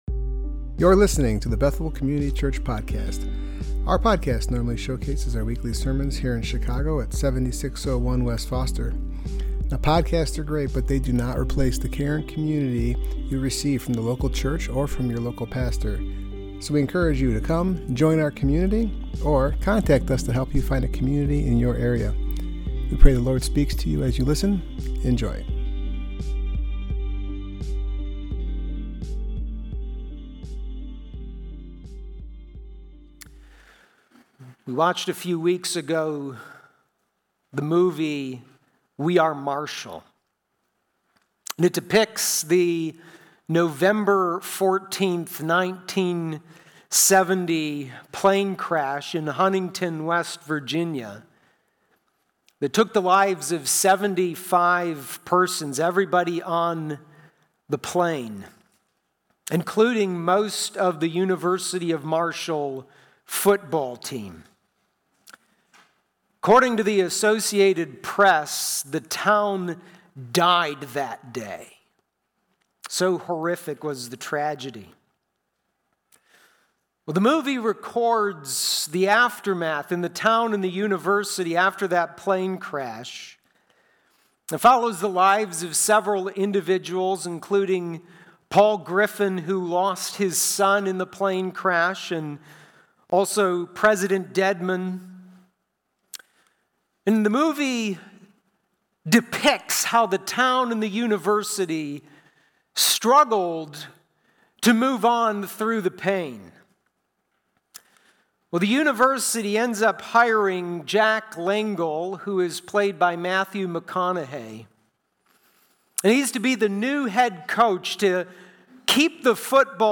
Bethel Community Church - Chicago – Podcasts Podcasts of our weekly sermons